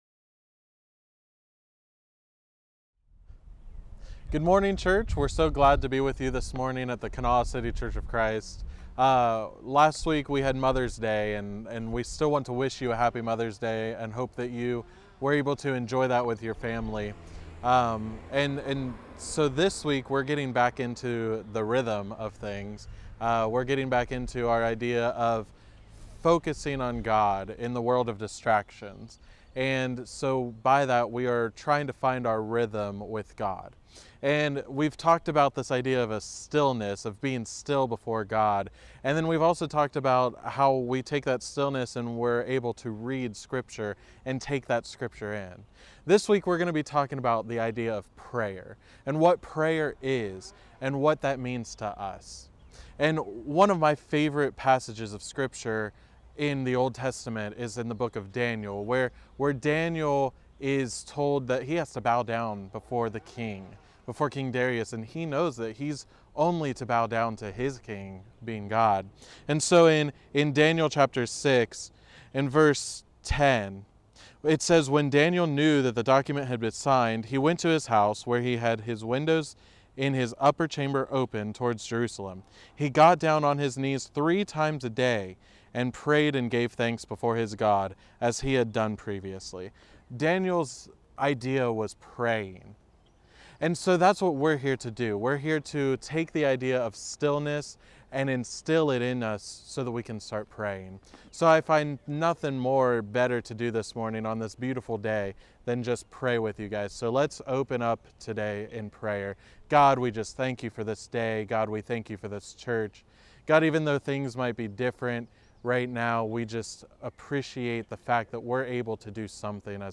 Date of Sermon